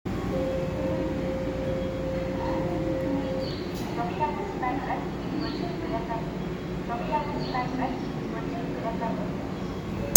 8000系 車載発車放送